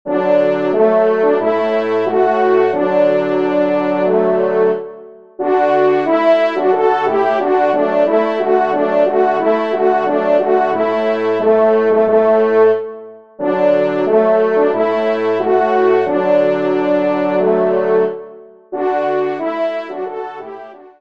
Genre : Divertissement pour Trompes ou Cors
ENSEMBLE